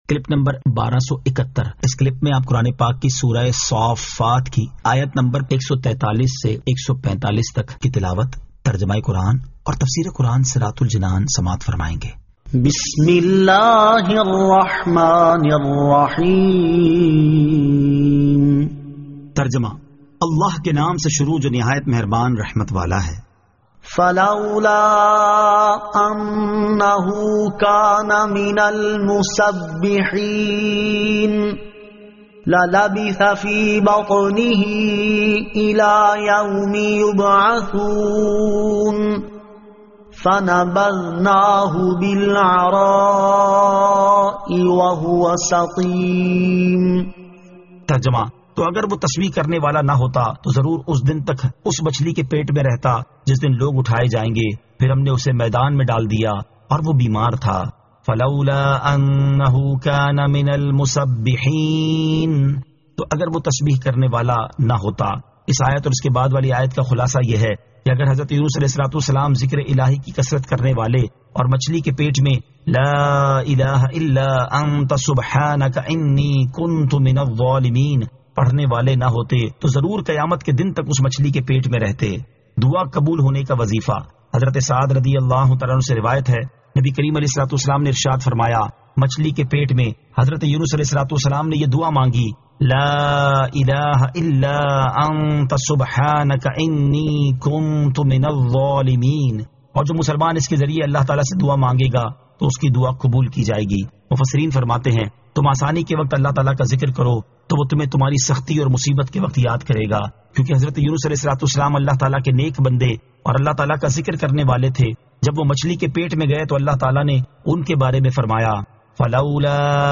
Surah As-Saaffat 143 To 145 Tilawat , Tarjama , Tafseer